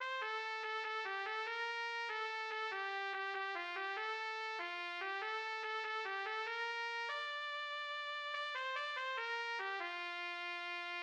jolly old folk song
MIDI rendition